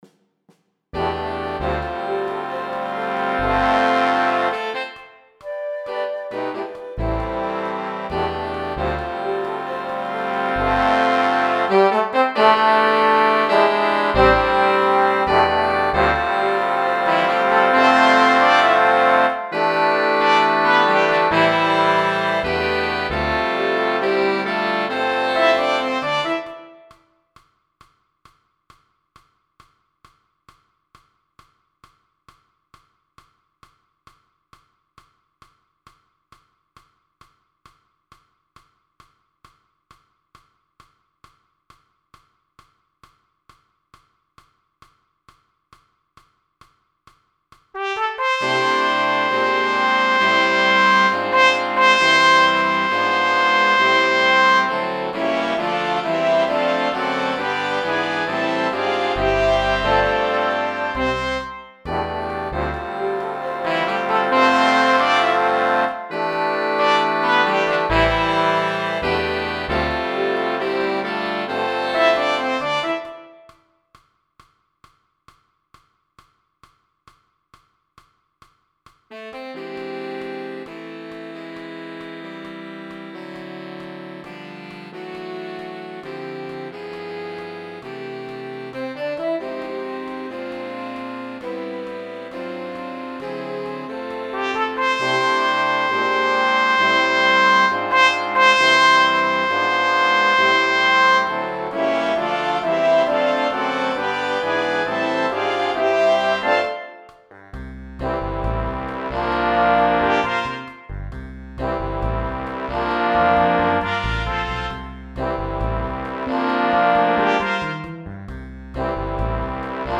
o Come All Ye Faithful Play along.mp3